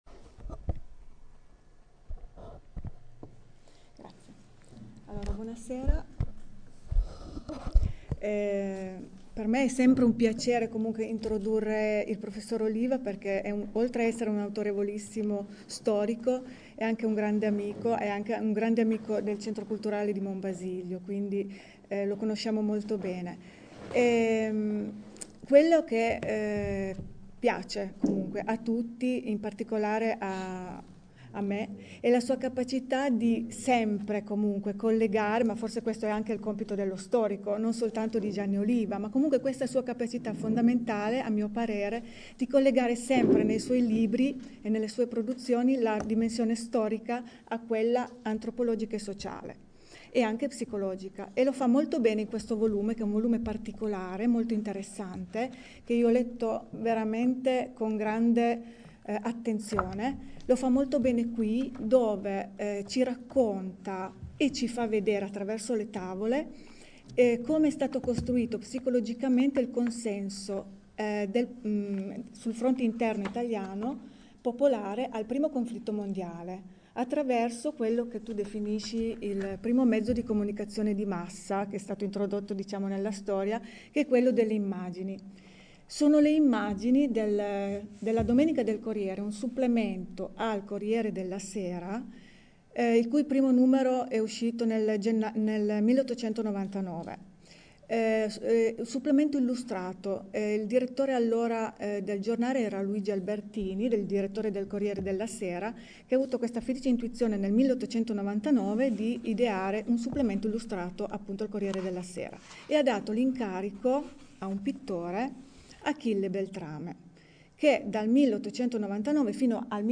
Venerdì 27 settembre 2013, presso il Museo Arti e Mestieri di un Tempo di Cisterna d’Asti, hanno preso avvio gli incontri promossi dal Polo Cittattiva per l’Astigiano e l’Albese.